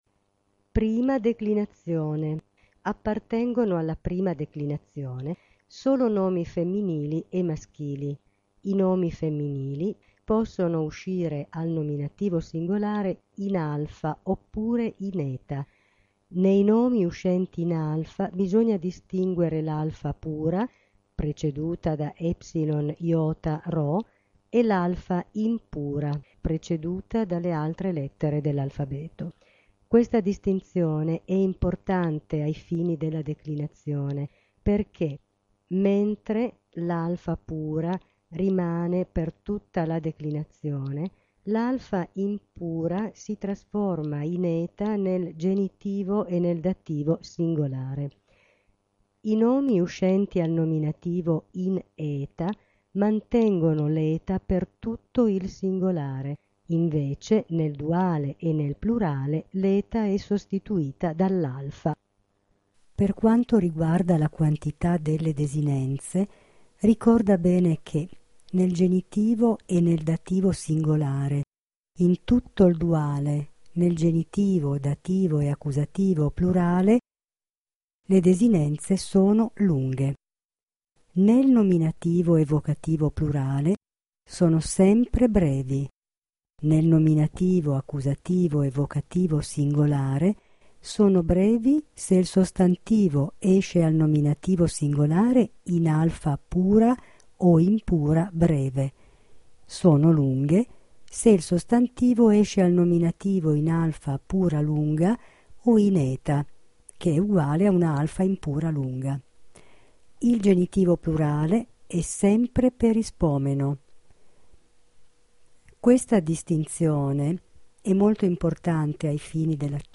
1.prima declinazione.mp3) permette di sentire la lettura della prima declinazione caratteri generali.